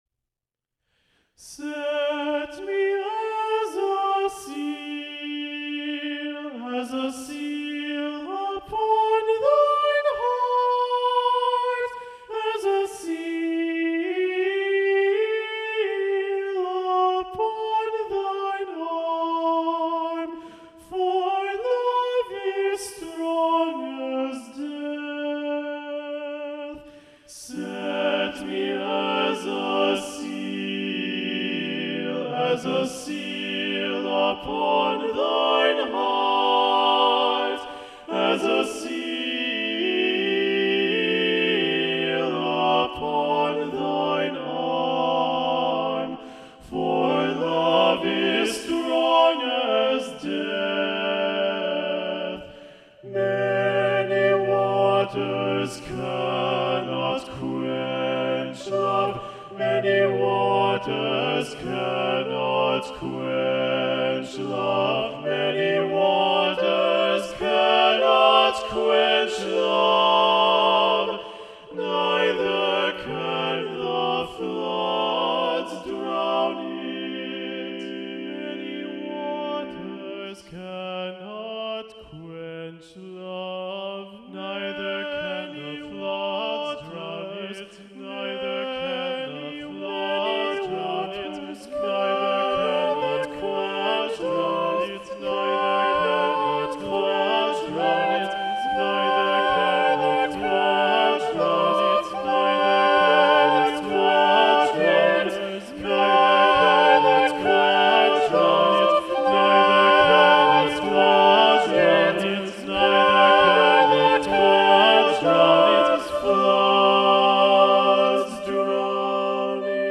for SATB unaccompanied